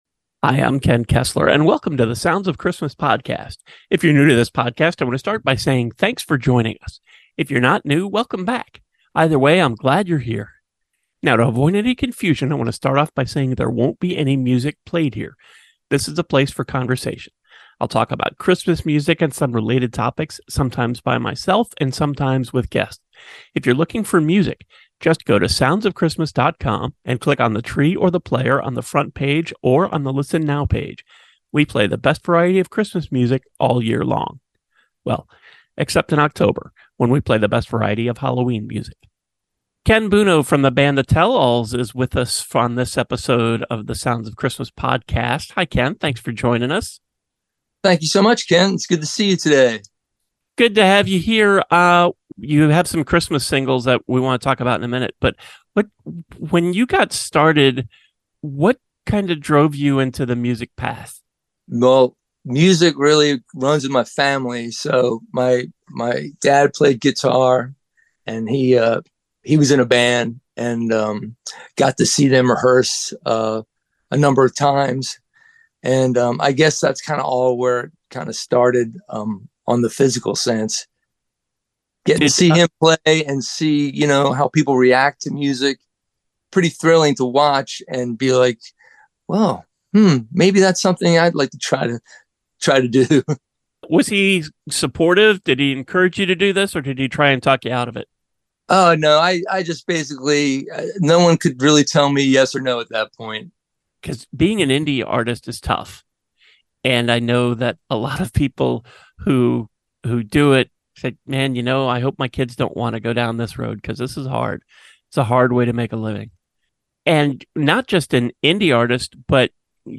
This week, your festive hosts are counting down each of their Top 10 Holiday Season Feelings that make this time of year so magical. From the warmth of nostalgia to the excitement of giving, we’re diving deep into what truly makes the season bright!